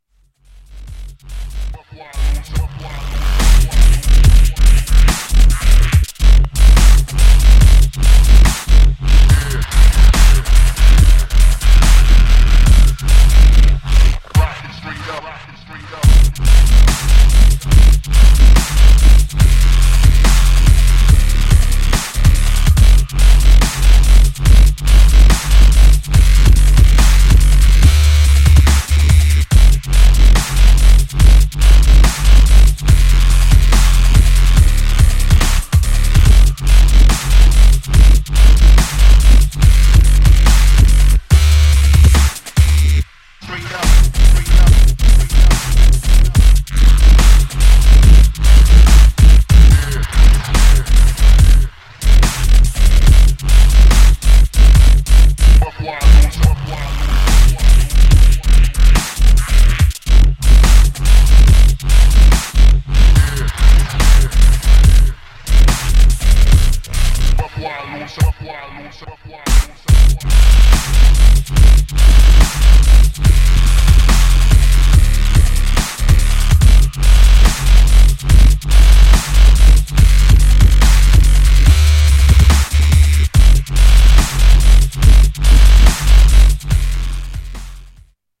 Styl: Dub/Dubstep, Drum'n'bass, Jungle/Ragga Jungle